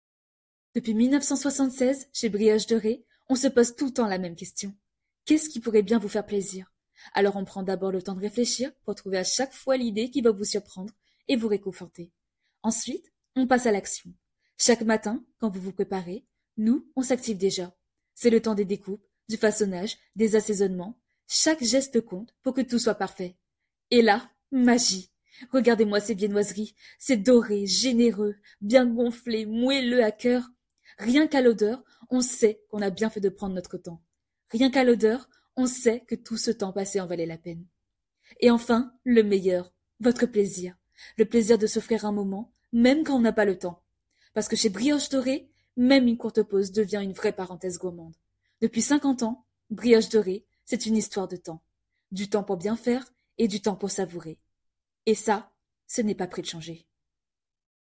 10 - 30 ans - Soprano